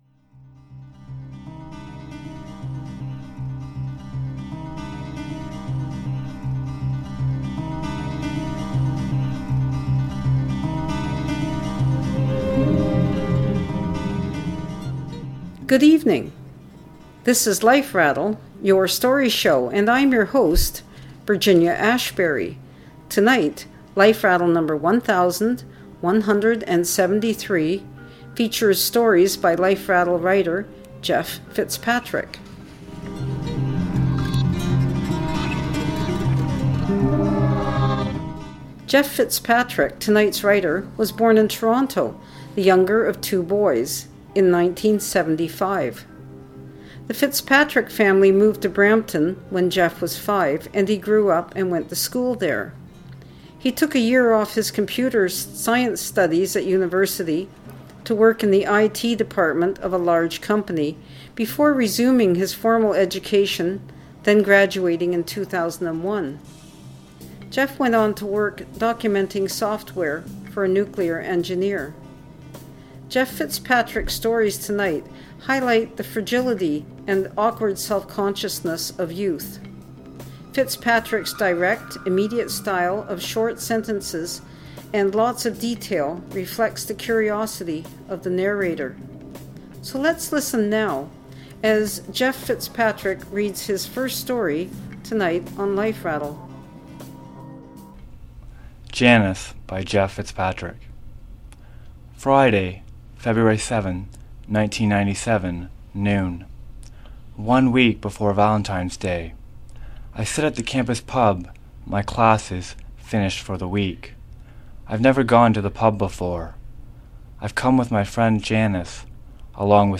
reading his stories